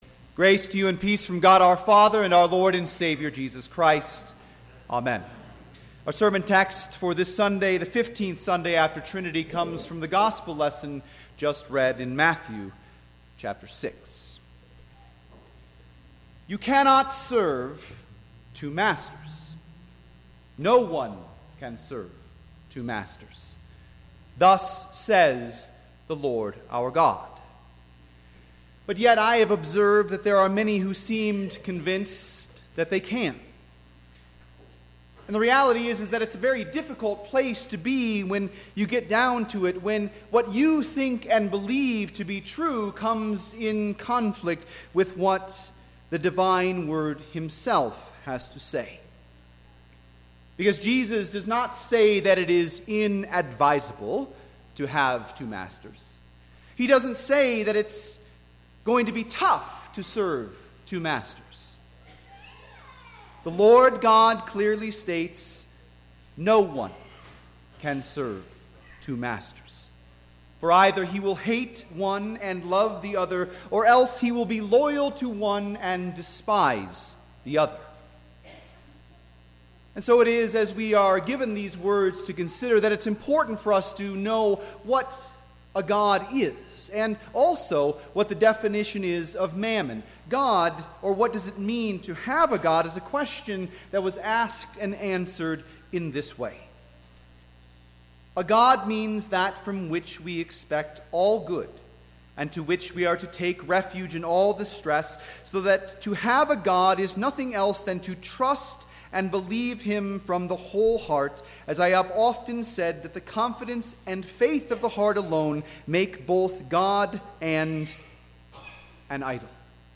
Sermon - 9/24/2017 - Wheat Ridge Evangelical Lutheran Church, Wheat Ridge, Colorado
Fifteenth Sunday after Trinity